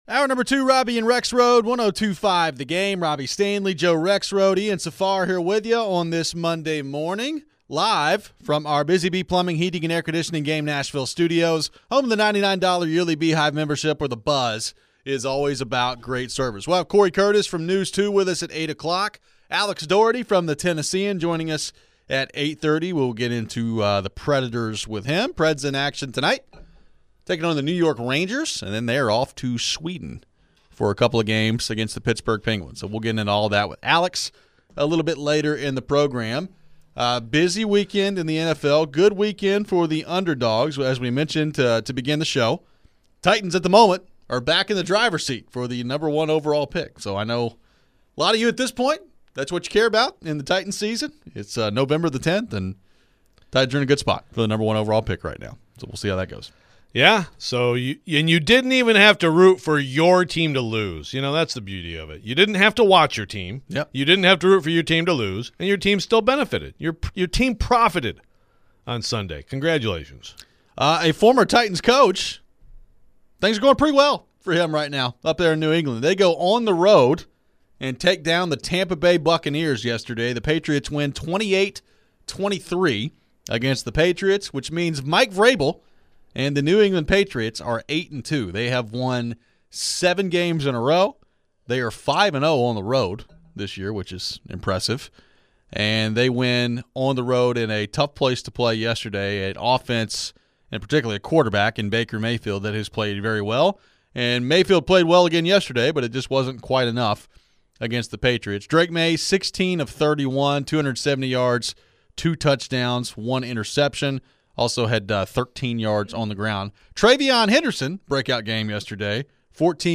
We get back into the weekend of NFL action and take your phones. How did the upcoming QB draft class look this week?